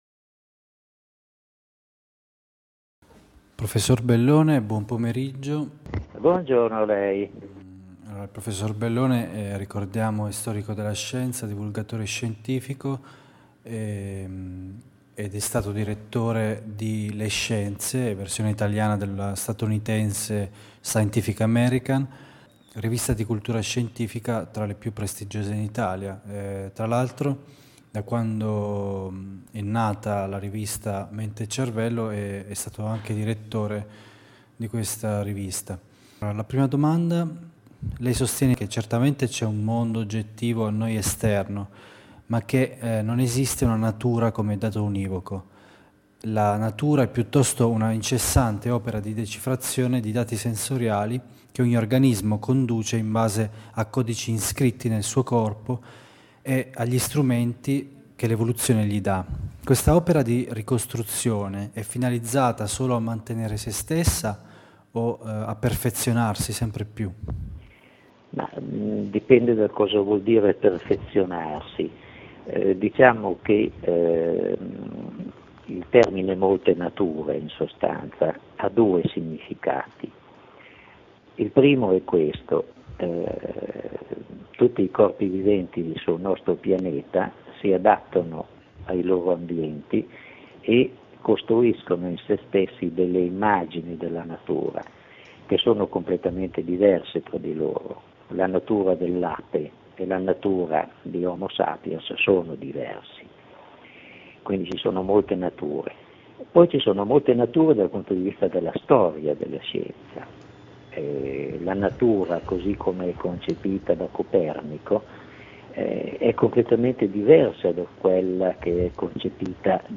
Intervista a Enrico Bellone
Abbiamo raggiunto telefonicamente il prof. Enrico Bellone, noto storico della Scienza, già direttore di Le Scienze e Mente&Cervello. I temi affrontati sono la natura del mondo oggettivo, il meccanismo dell'evoluzione culturale, la presunta centralità dell'uomo nel mondo. Abbiamo affrontato anche il tema della ricerca scientifica in Italia.